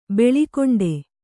♪ beḷikoṇḍe